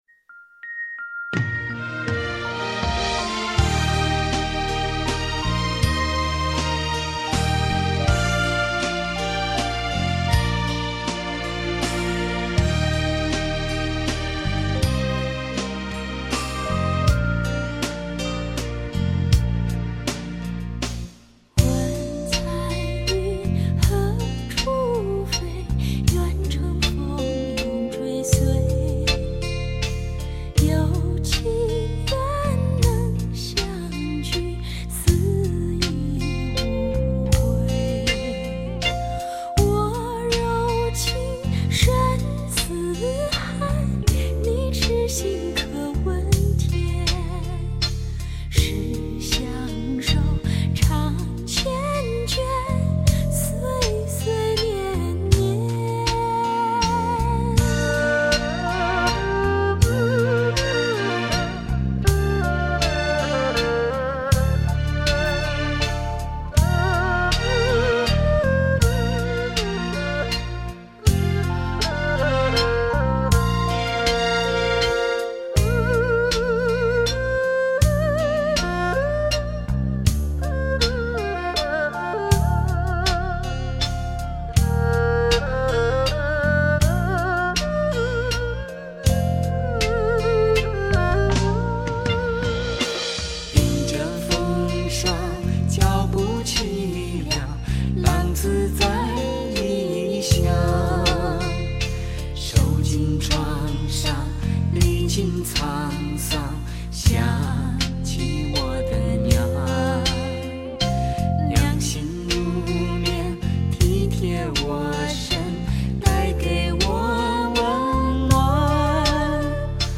慢三